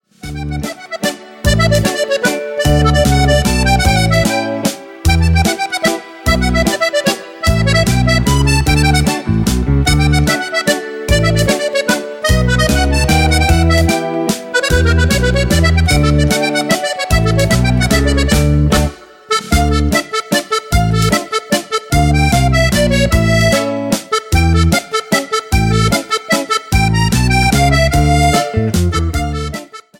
MAZURCA  (02.19)